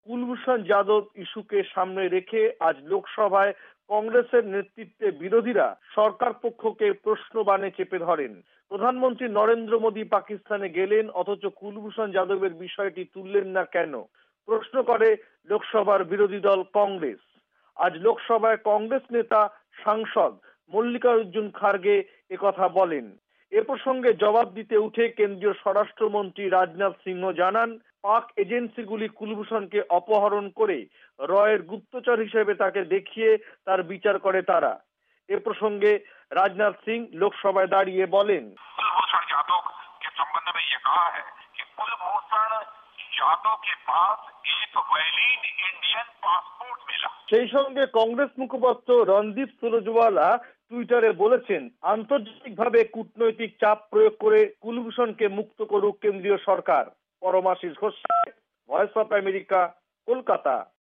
বিস্তারিত জানাচ্ছেন কলকাতা থেকে
রিপোর্ট